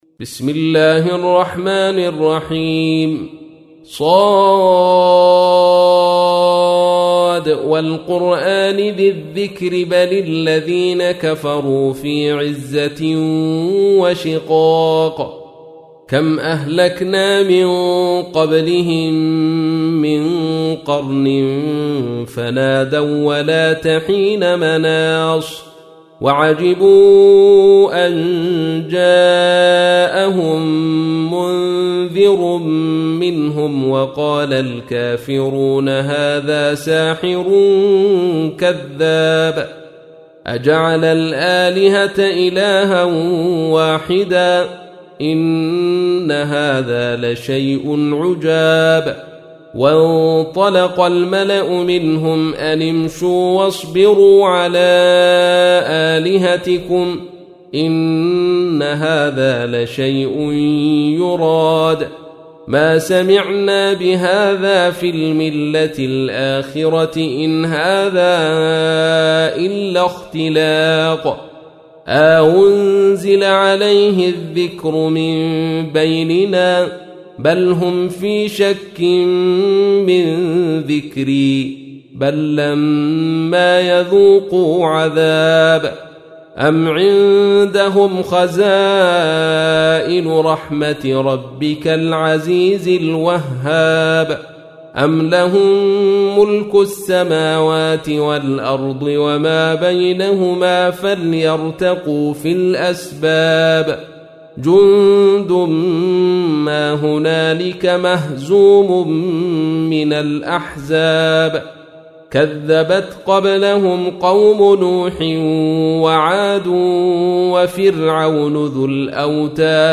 تحميل : 38. سورة ص / القارئ عبد الرشيد صوفي / القرآن الكريم / موقع يا حسين